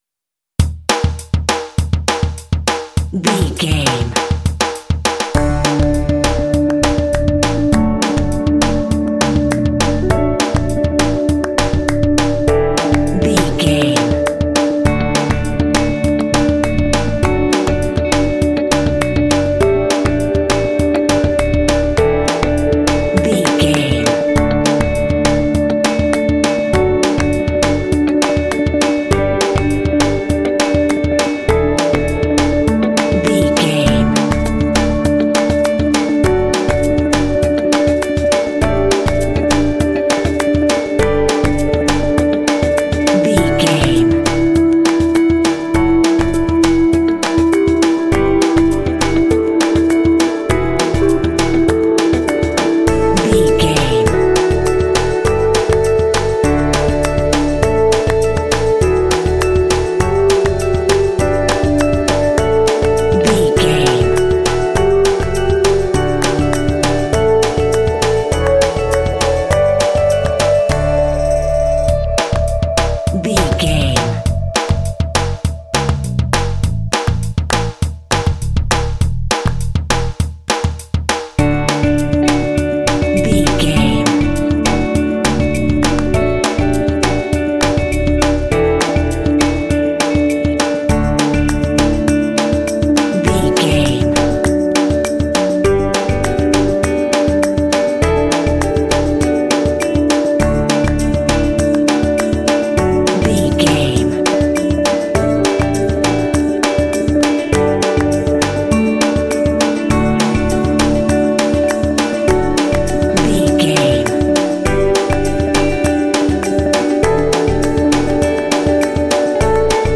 Ionian/Major
uplifting
sentimental
joyful
hopeful
bright
reflective
repetitive
piano
synthesiser
electric guitar
bass guitar
drums
percussion
contemporary underscore
indie